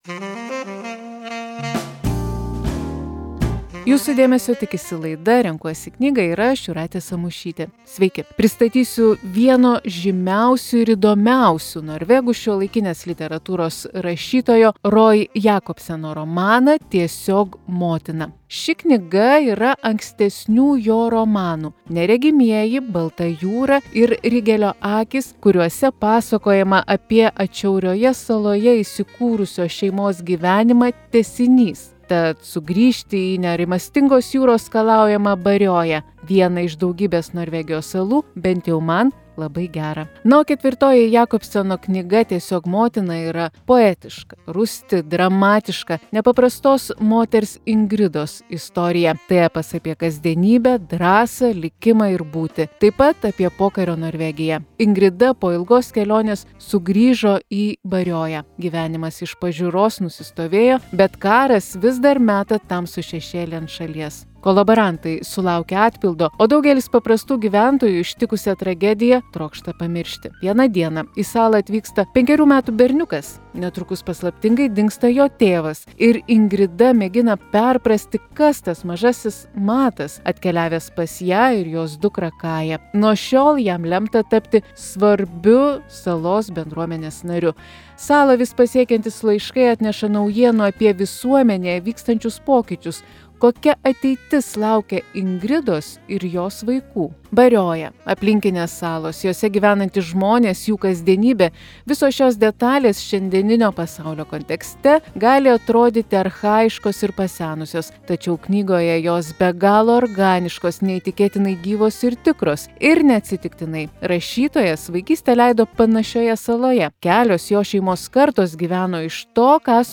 Knygos apžvalga.